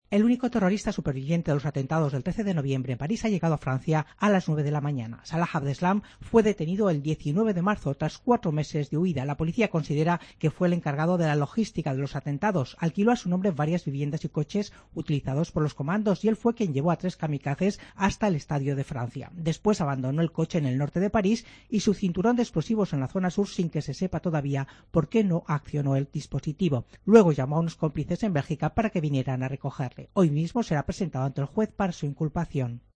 Mediodía COPE Abdeslam, extraditado a Francia. Crónica